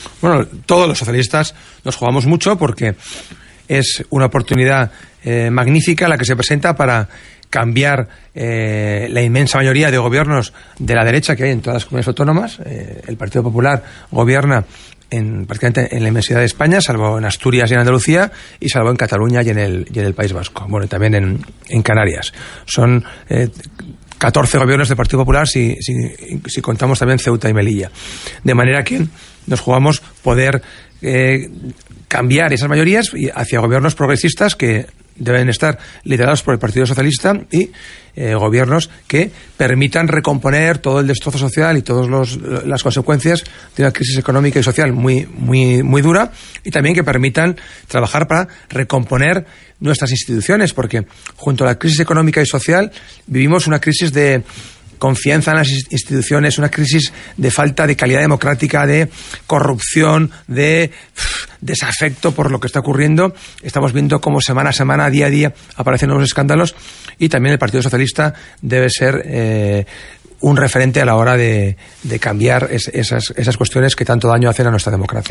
Fragmento de la entrevista en RNE 27/04/2015. Juan Moscoso reflexiona sobre lo que se juega España en las próximas elecciones municipales y autonómicas.